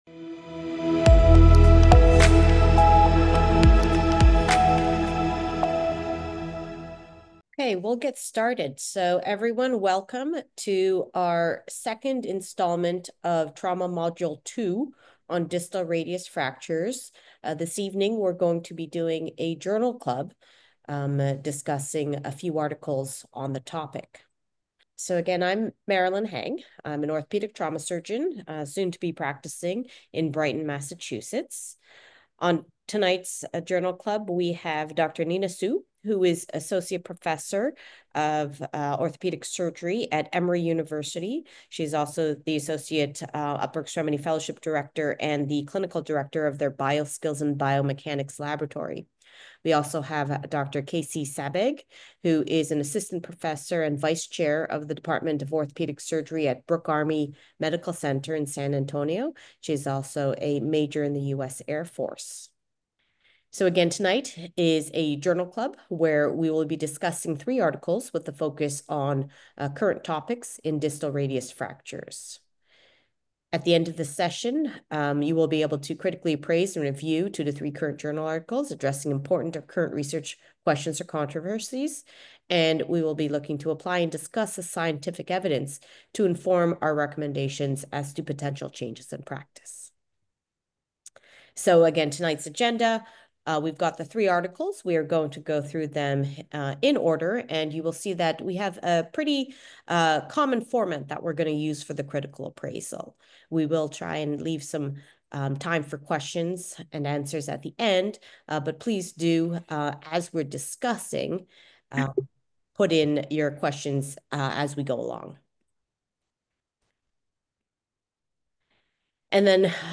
During this monthly series, interviews with authors of landmark orthopaedic trauma articles will be featured and discussed. The series will be based on anatomic location/injuries and will provide an opportunity to understand what prompted the study, how practice has changed, limitations and key take away points.